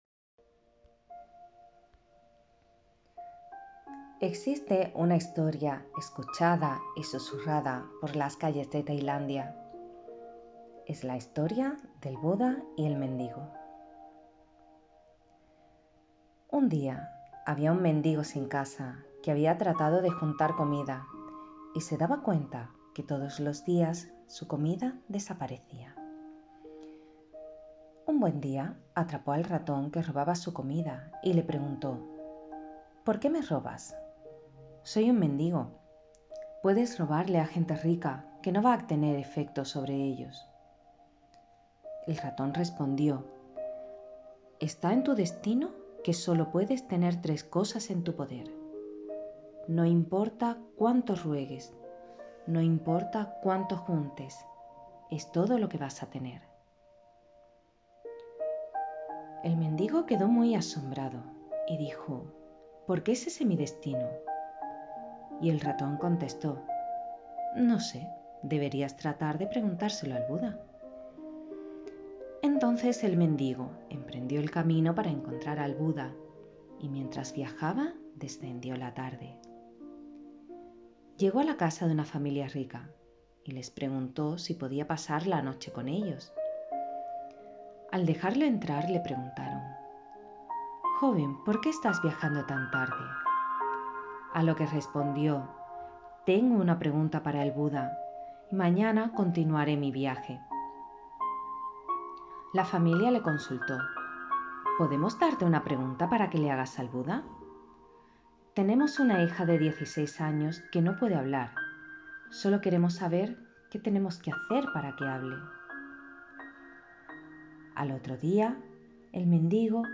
Mientras tanto, disfruta de las historias que te iré narrando.